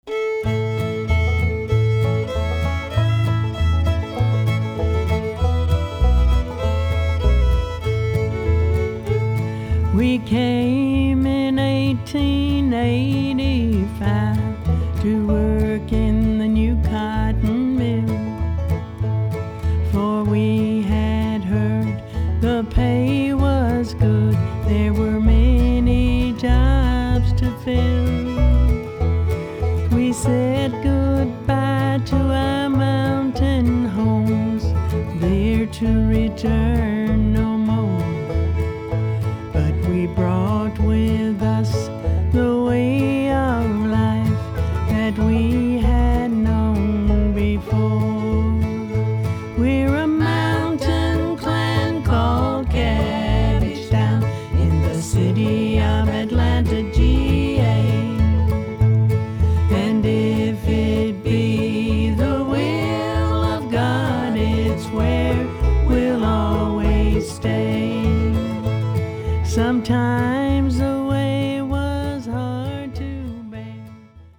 harmony vocals
piano
guitars